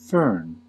Ääntäminen
US : IPA : /fɝn/